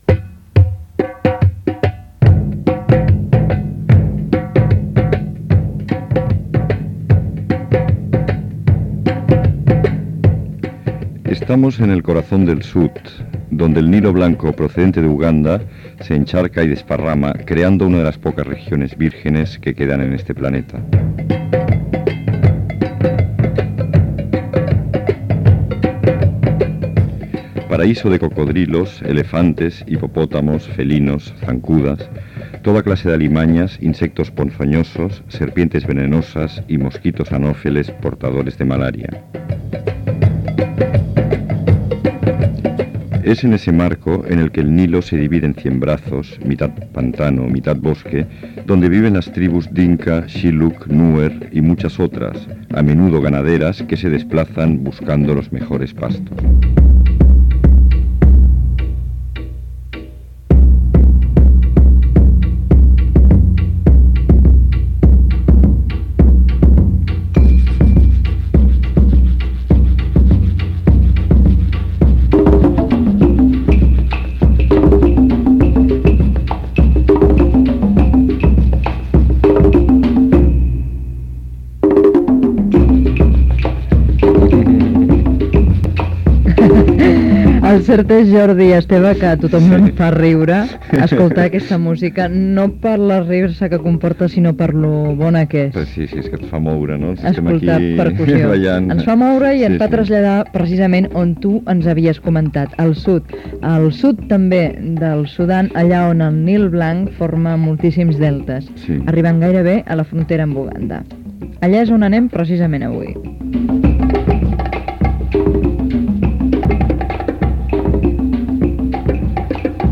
Nom programa La última ola Descripció Espai dedicat al sud del Sudan Gènere radiofònic Entreteniment